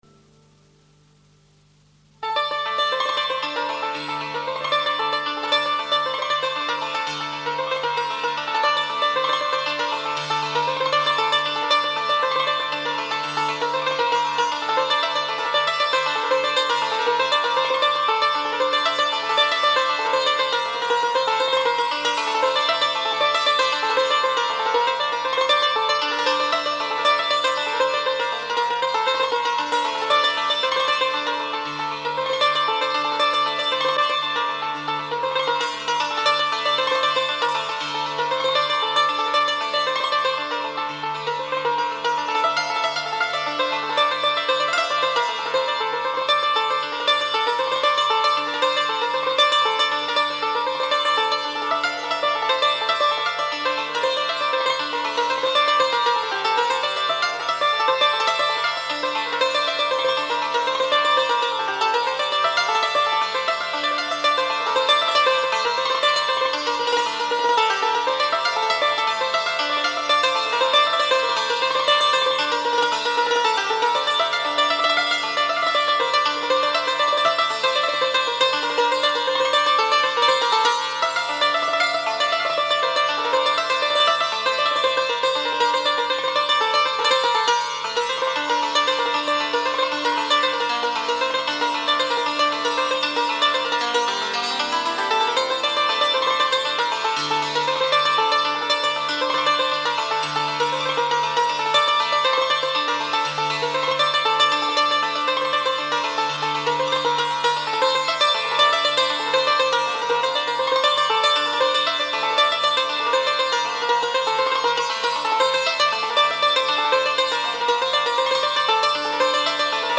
Santoor